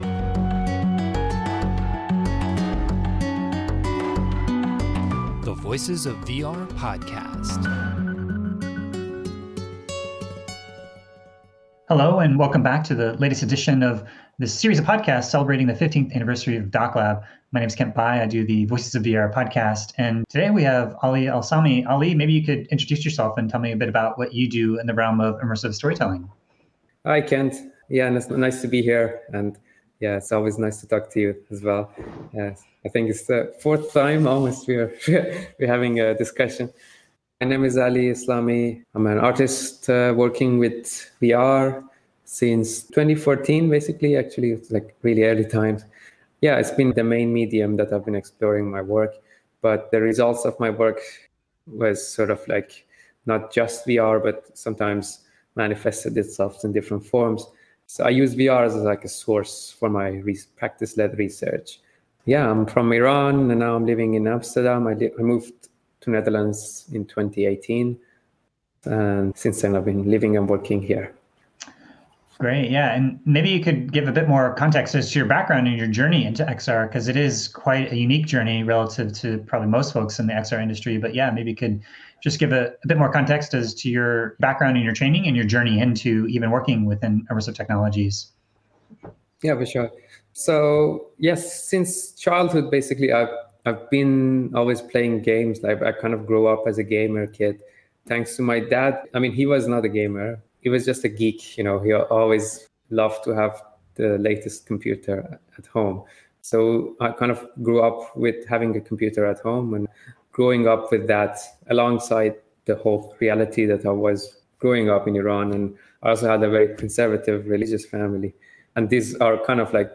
This was recorded on Friday, December 3, 2021 as a part of a collaboration with IDFA’s DocLab to celebrate their 15th year anniversary.